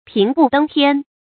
平步登天 注音： 讀音讀法： 意思解釋： 比喻一下子就達到很高的地位或境界。